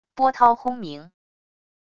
波涛轰鸣wav音频